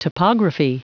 Prononciation du mot topography en anglais (fichier audio)
Prononciation du mot : topography